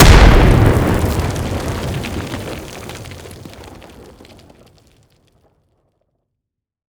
at4rpg_detonate_02.wav